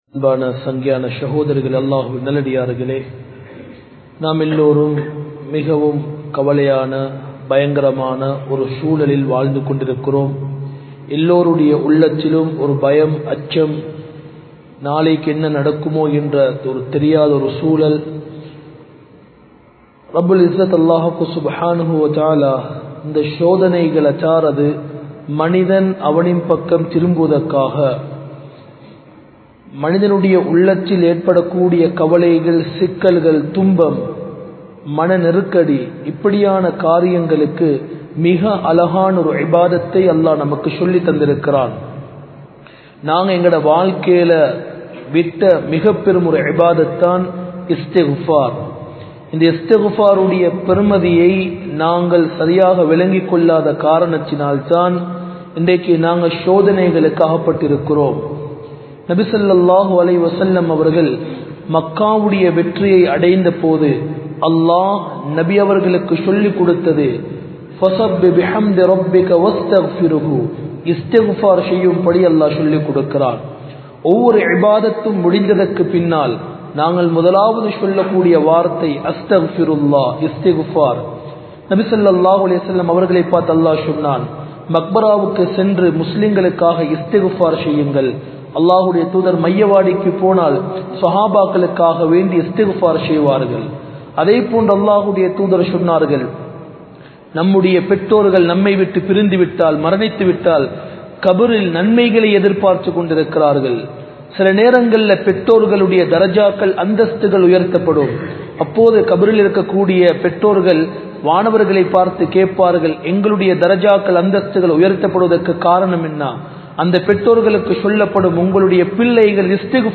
Bayans
Colombo 04, Majma Ul Khairah Jumua Masjith (Nimal Road)